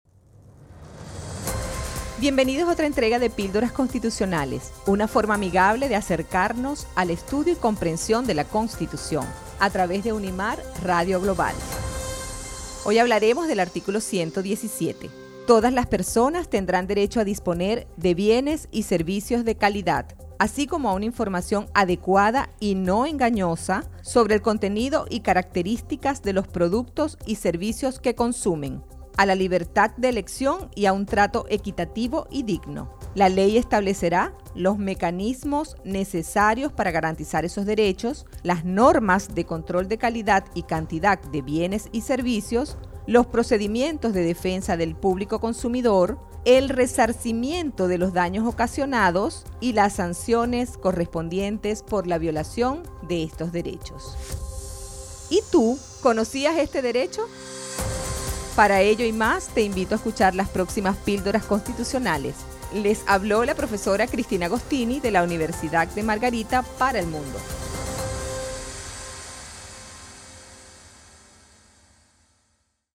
En esta emocionante serie de micros radiales, te invitamos a descubrir la importancia de la Constitución de la República Bolivariana de Venezuela de una manera fresca, divertida y fácil de entender.